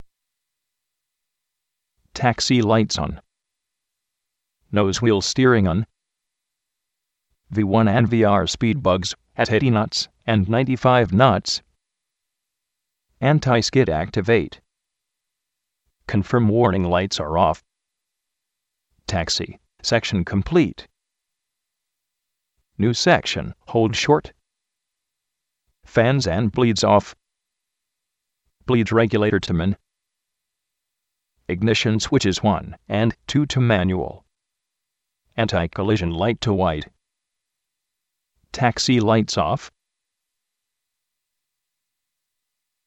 The best voices are the exceptional payware Natural Voices by AT&T. However unnatural sounding, however, the free Microsoft voices are quite usable.
They are all actual output from the LWA audio checklist function.
AT&T 16 kHz Natural Voice Mike (payware)
ATT Natural Voice Mike16.mp3